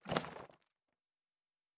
pgs/Assets/Audio/Fantasy Interface Sounds/Book 04.wav at master